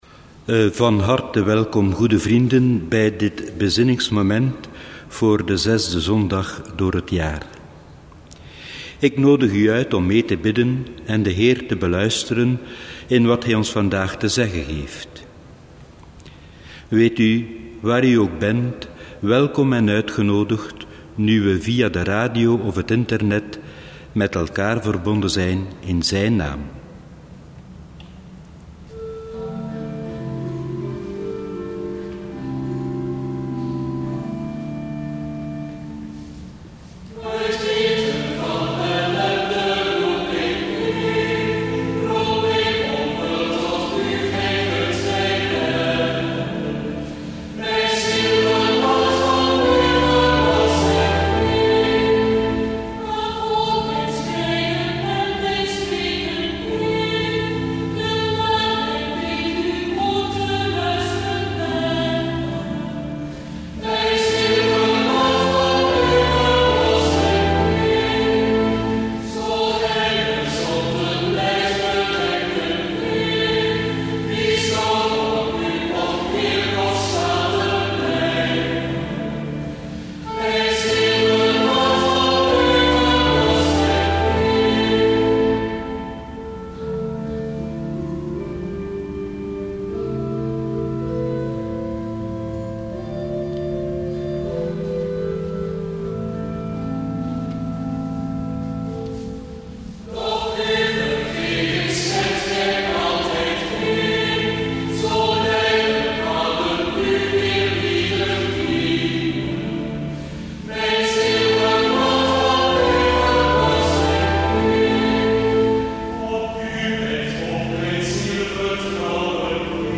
En je hier ook het liedblad downloaden zodat je ook kan meezingen.